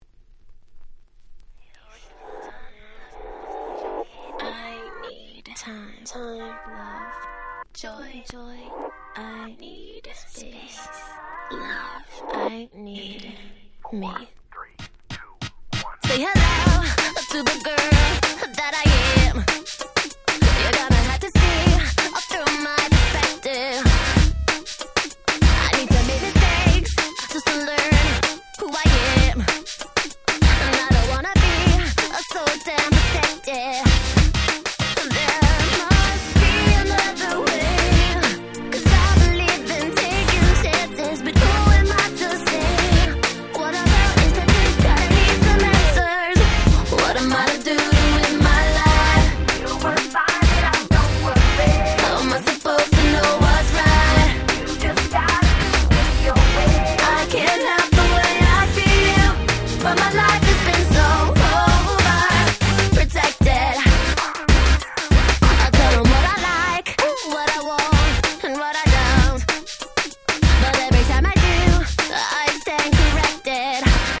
02' Smash Hit R&B / Pops !!
00's キャッチー系